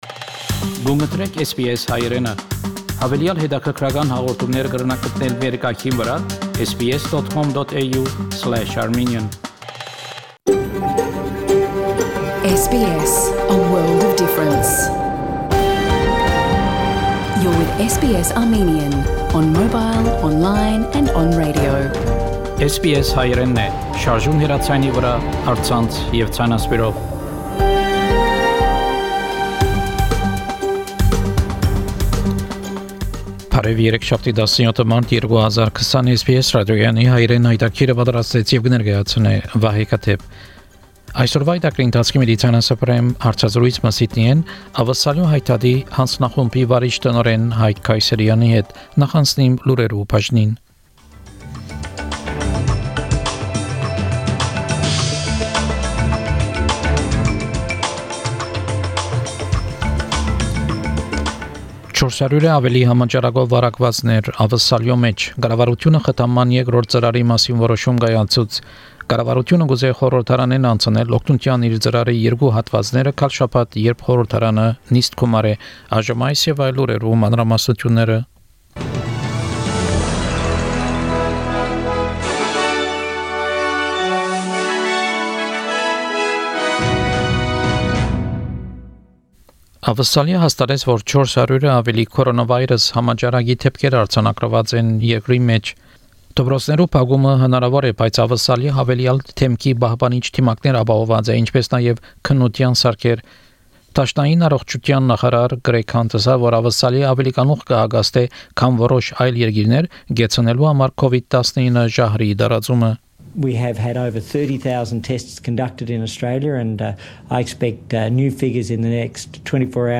Armenian news bulletin - March 17